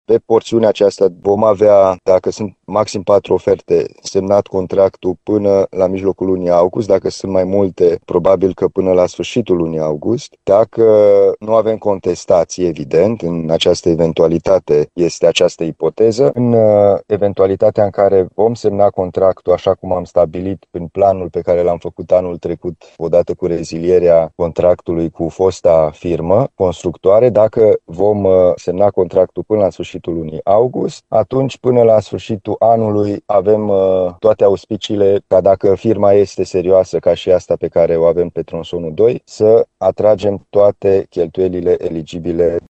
Consiliul Județean Timiș intenționează să semneze contractul până la sfârșitul lunii august, astfel încât lucrările să poată fi încheiate până la finalul anului. În felul acesta, autoritățile nu ar pierde finanțarea europeană, spune președintele Consiliului Județean Timiș, Alin Nica.